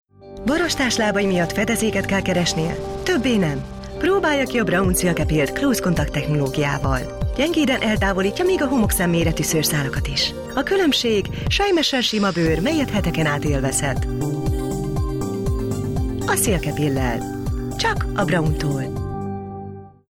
Hungarian - Female
Commercial, Smooth, Cool, Aspirational